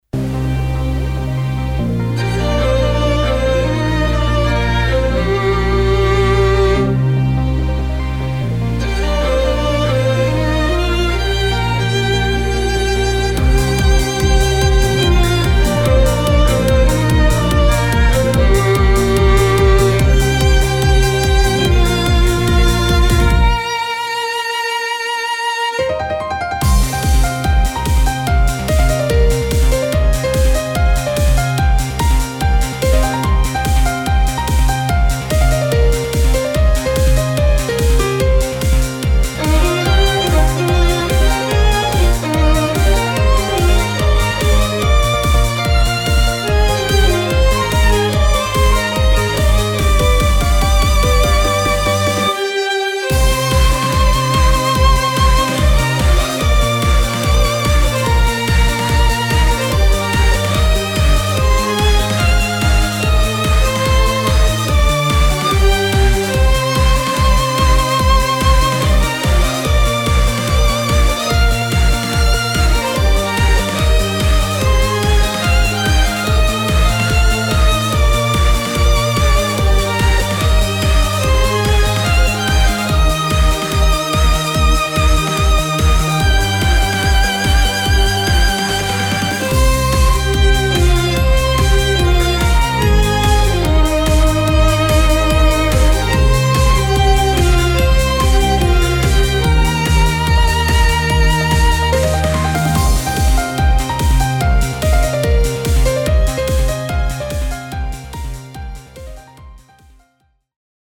フリーBGM イベントシーン 熱い・高揚
フェードアウト版のmp3を、こちらのページにて無料で配布しています。